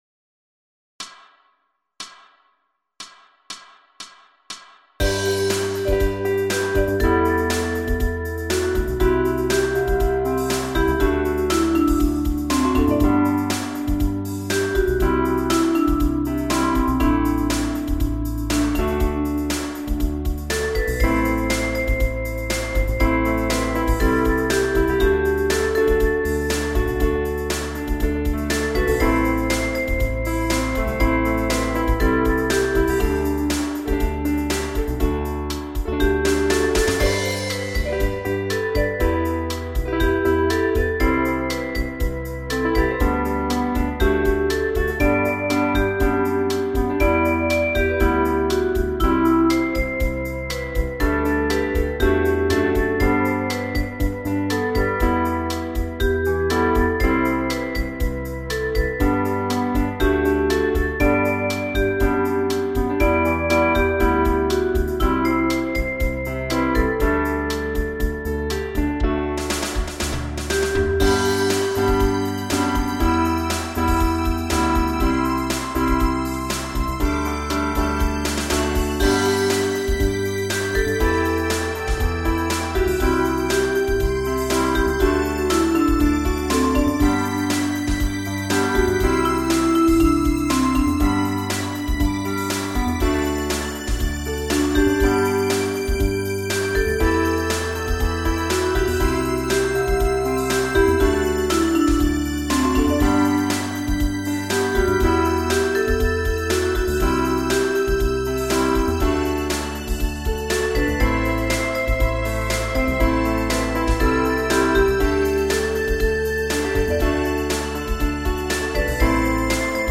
04_angel_met_solo.mp3